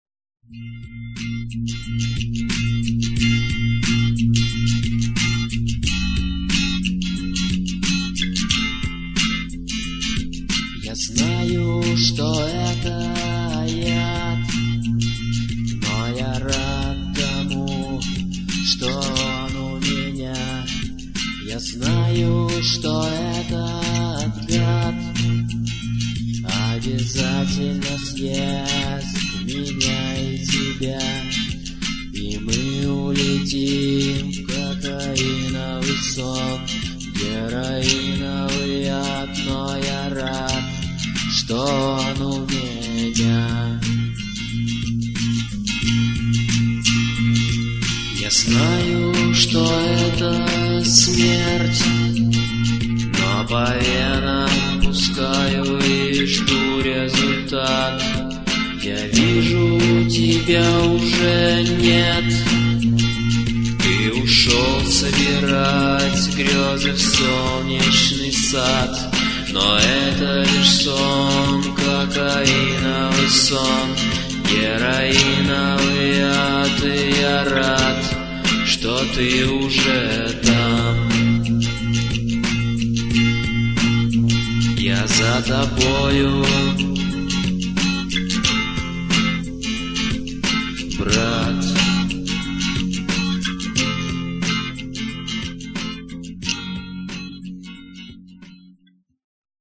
комп-версия